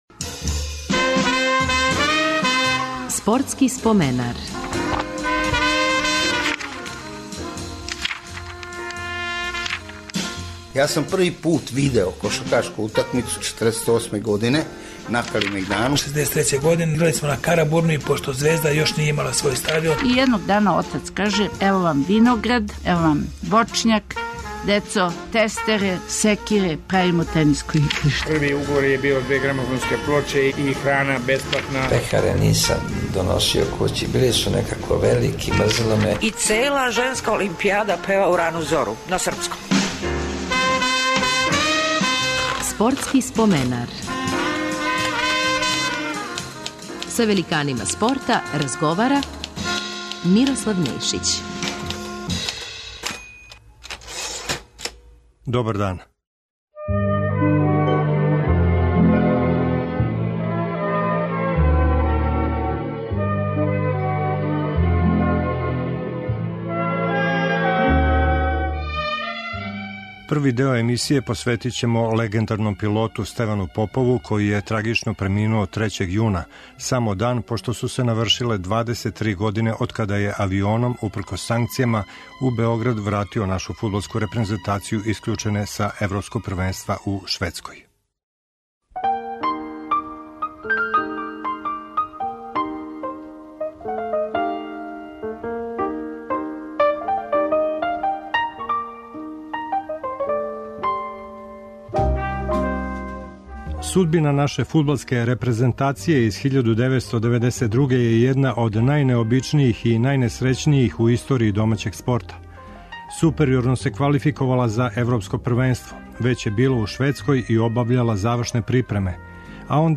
У нашем тонском архиву пронашли смо његова сведочења о формирању Партизана октобра 1945. првој генерацији играча и тренера, дербијима велике четворке, фудбалском турниру на Олимпијским играма у Лондону 1948.